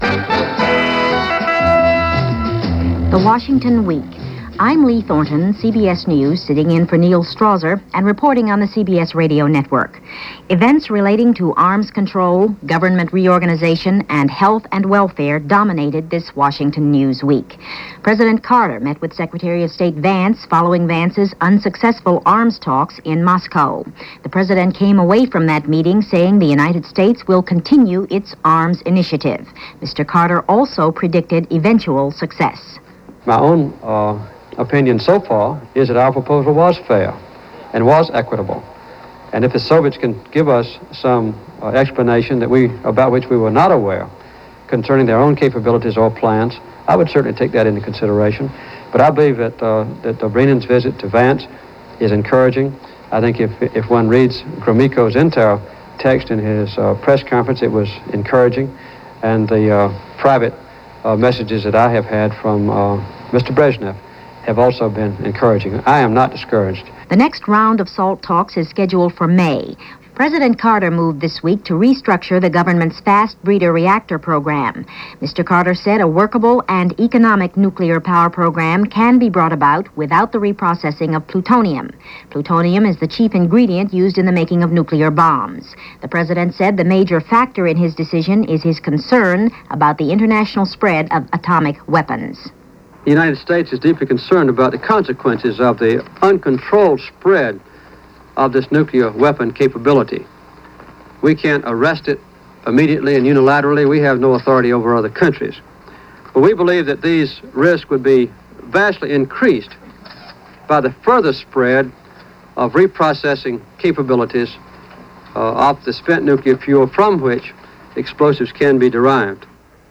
Carter And Sadat - Carter And SALT - Rabin Resigns - April 9, 1977 - Washington Week and World This Week - CBS Radio.